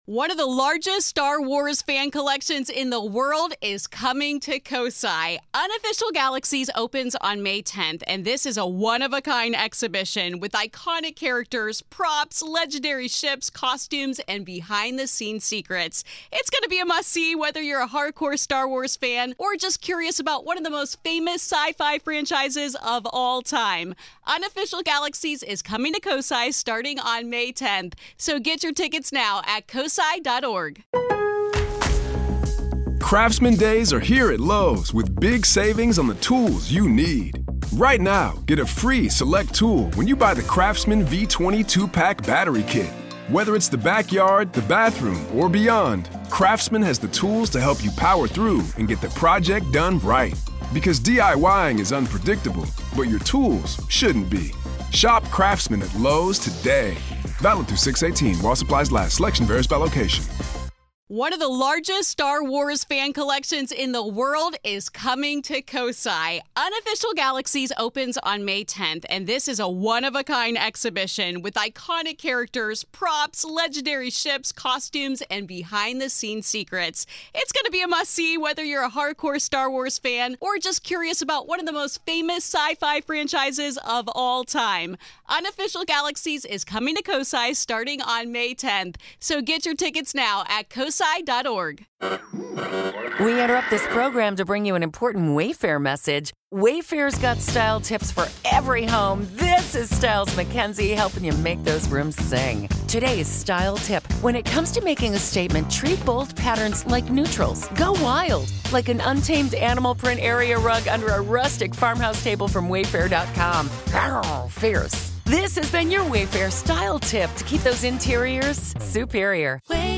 History and hauntings collide in this captivating discussion.